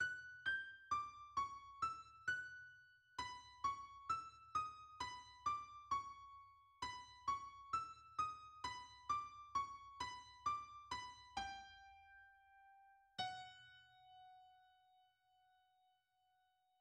Closing theme (mm. 61–64)